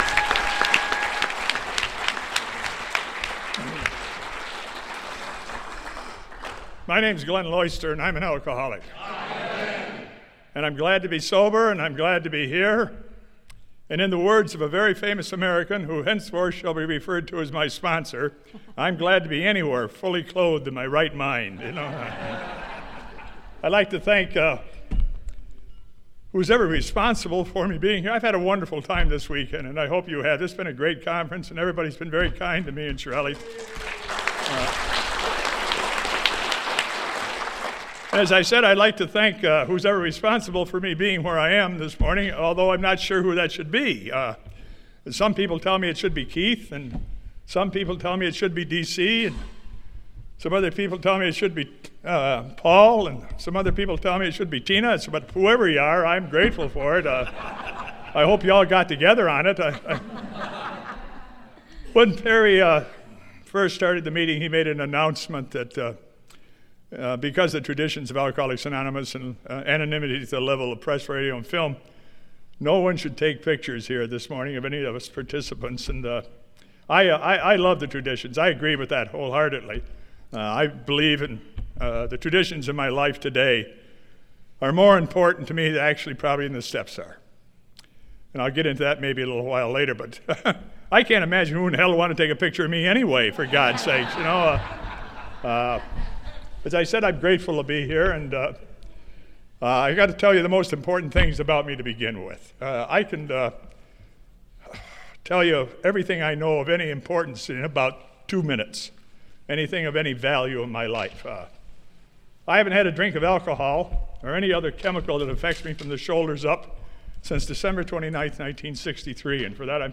Sunday AA Speaker